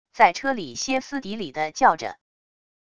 在车里歇斯底里地叫着wav音频